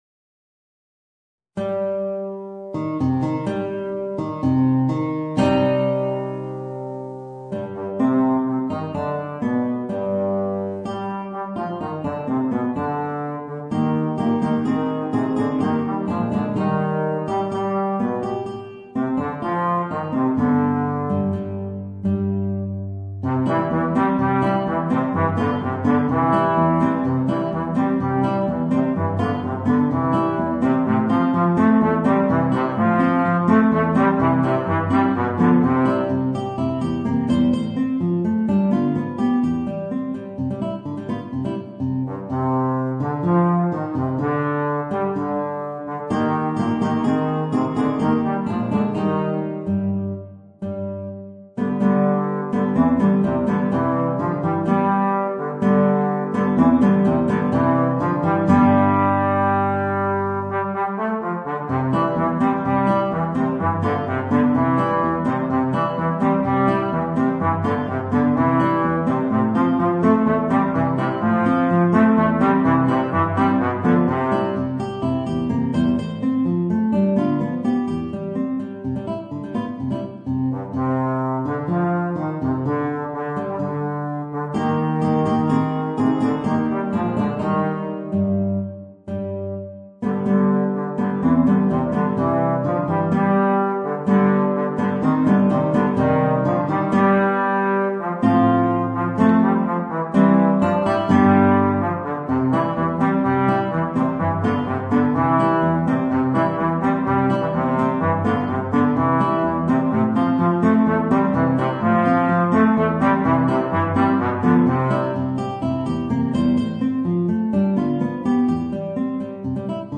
Voicing: Bass Trombone and Guitar